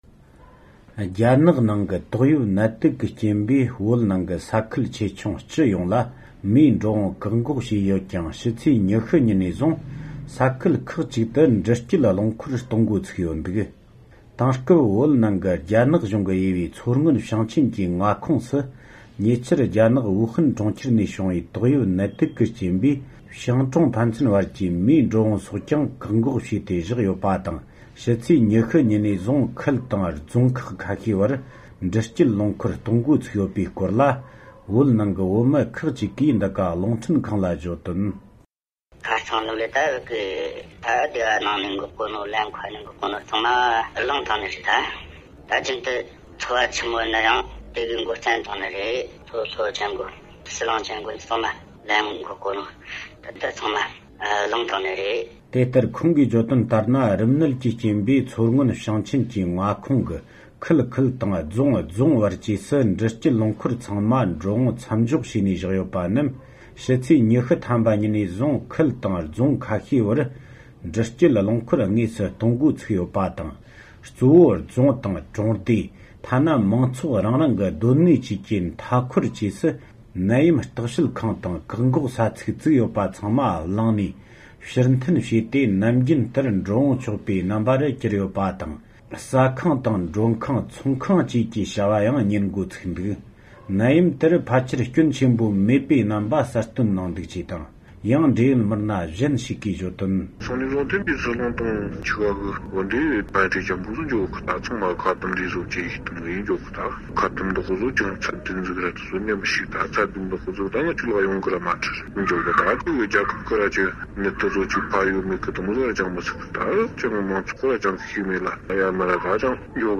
བལ་ཡུལ་ནས་སྤེལ་བའི་གནས་ཚུལ་ཞིག